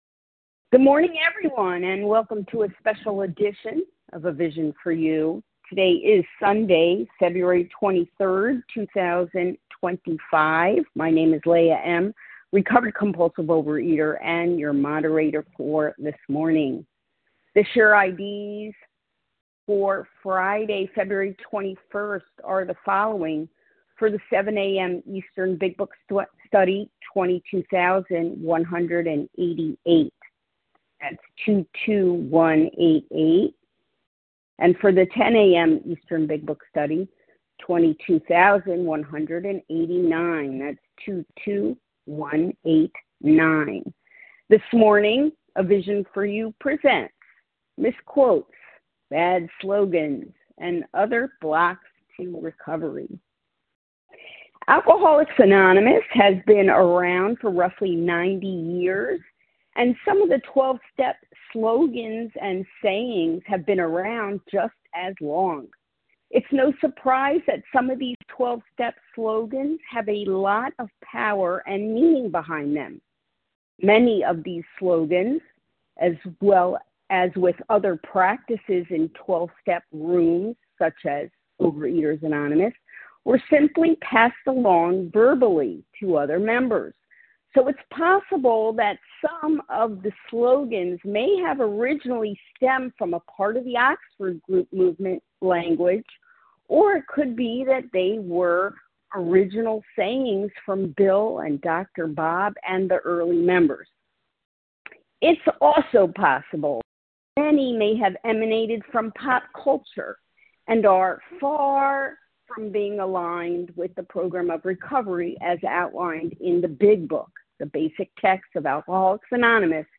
Overeaters Anonymous members share their experience, strength and hope on a number of different topics.